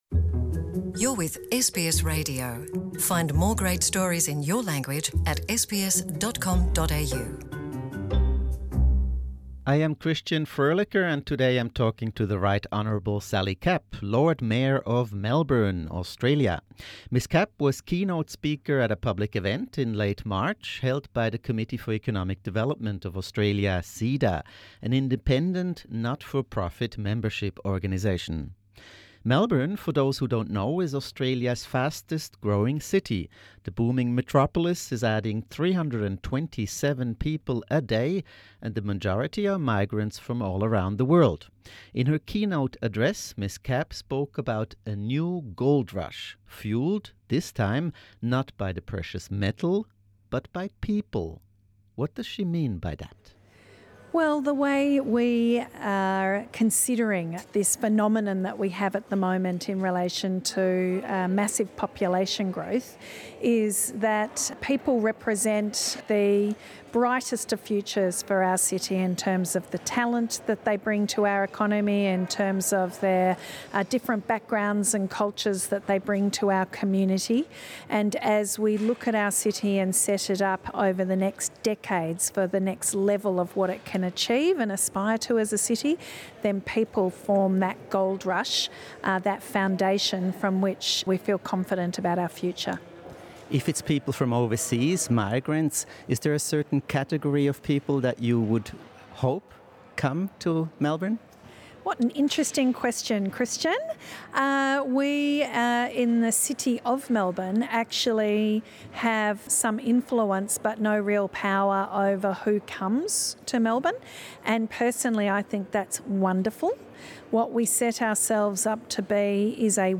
How is Australia's fastest growing city coping with this influx? To find out, we caught up with Sally Capp, the first woman to be directly elected as Lord Mayor of Melbourne.